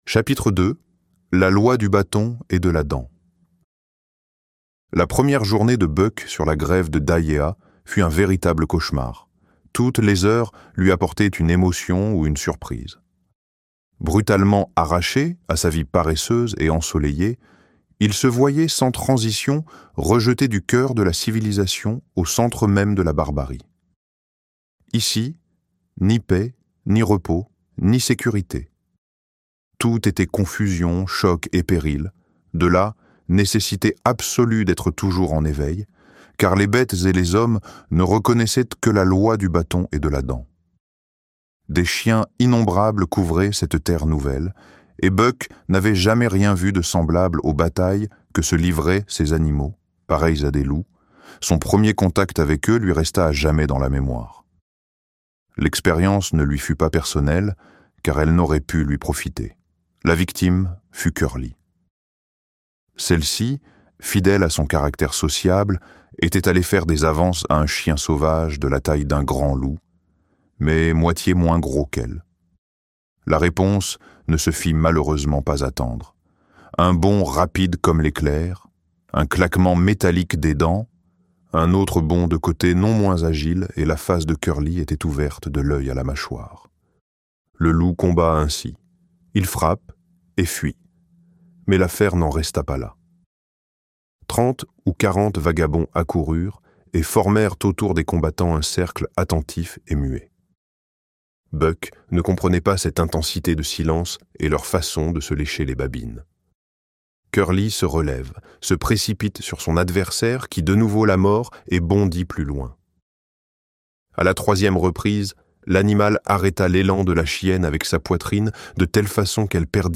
L'Appel de la forêt - Livre Audio